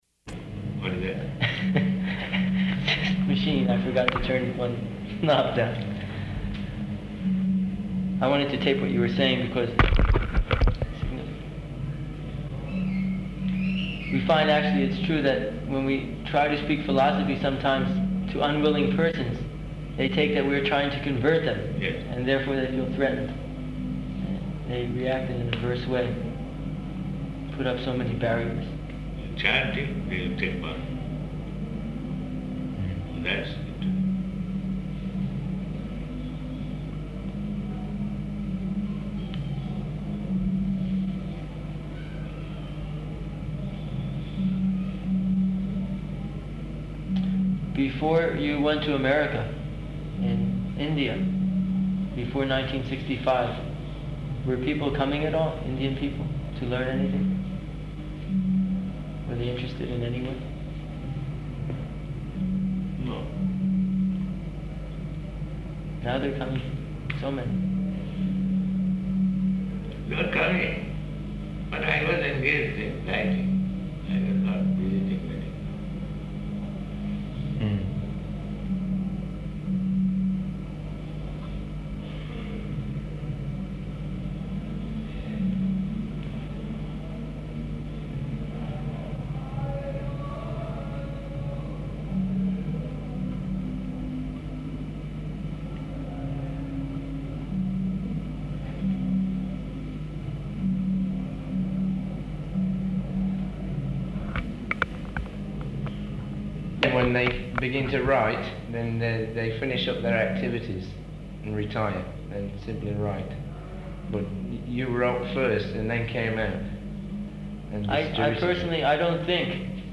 Room Conversation
Location: Melbourne